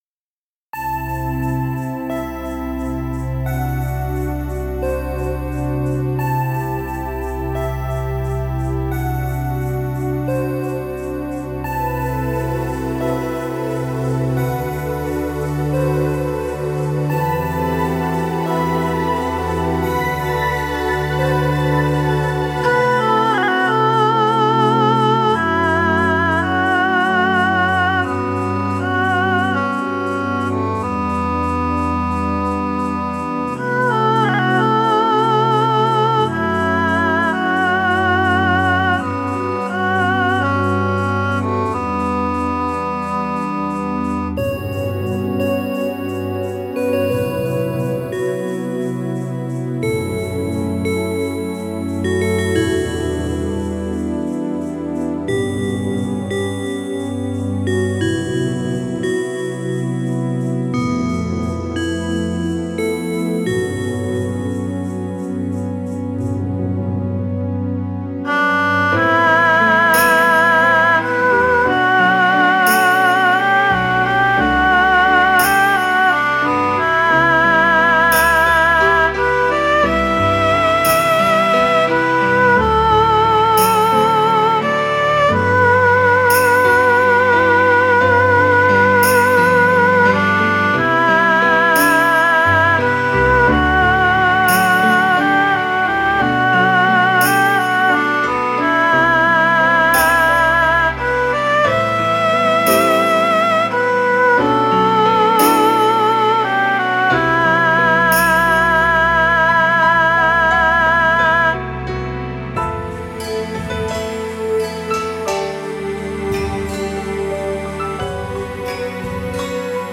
「インスト部門」最優秀作品です。